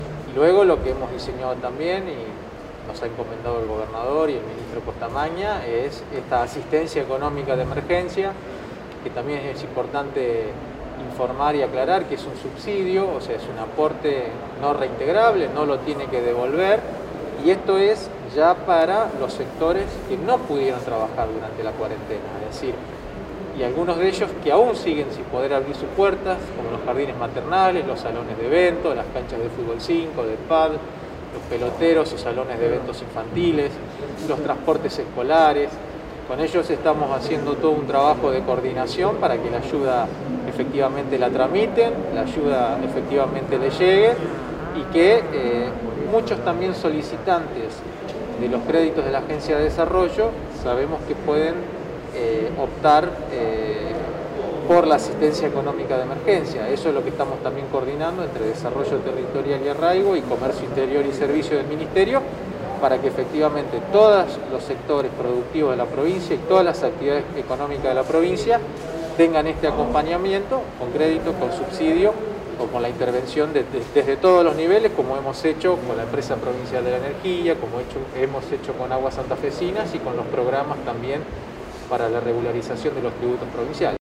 El secretario de Comercio Interior y Servicios de la provincia, Juan Marcos Aviano